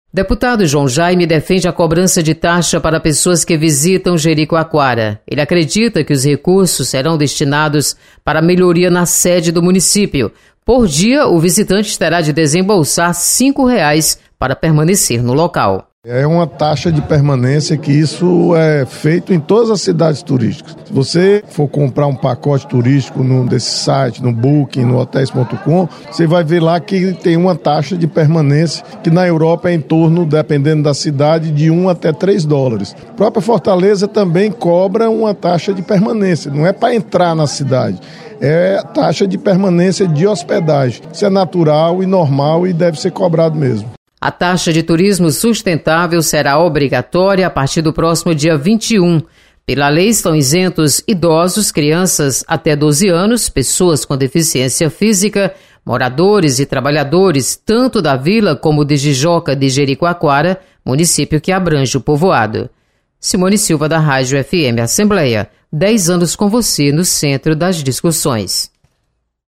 Deputado João Jaime defende cobrança de taxa dos que visitam Jericoacoara. Repórter